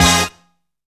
FIELD STAB.wav